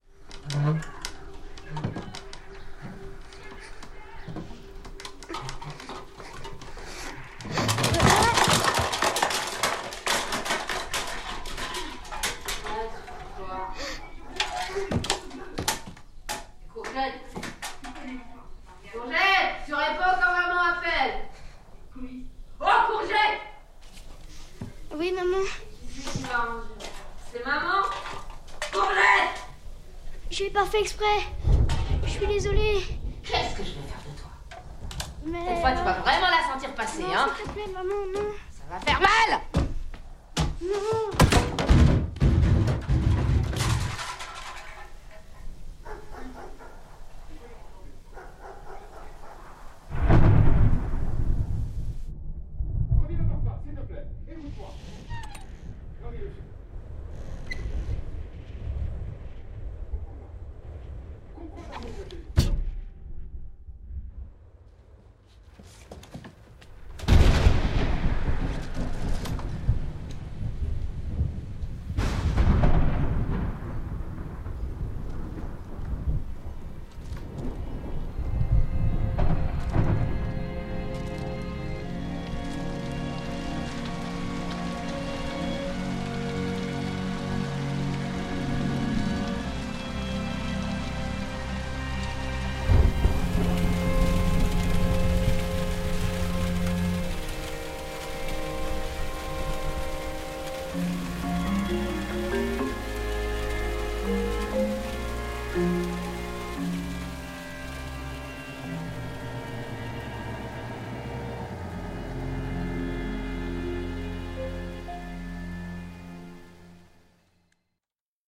Émettre des hypothèses sur le film et l’histoire à partir d’extraits de la bande-son (bruitages, dialogues, musique).
Des grincements puis une montagne d’objets (des cannettes) s’écroule dans un vacarme qui couvre presque le petit cri d’un enfant.
Il répond, on sent par sa voix qu’il a très peur. Elle s’énerve, elle a un ton horrible, menaçant, sa voix est imbibée par l’alcool.
Un drame terrible s’est joué ici, illustré par le grondement des éclairs (violence), la pluie (larmes) et une musique néo-baroque qui nous dit la tristesse de la scène et en souligne le caractère irrémédiable.